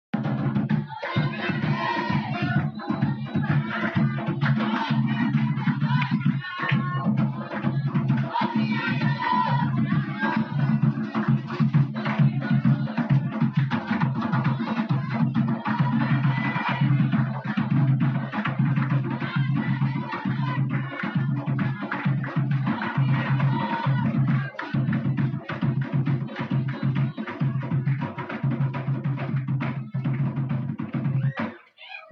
Cantiga Cosme e Damião.mp3